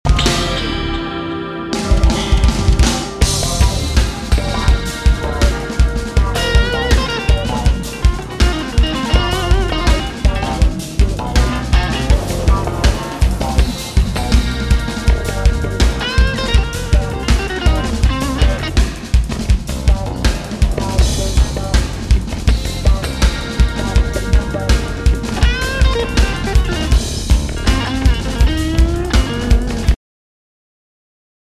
Recorded and mixed at Studio Ollersbach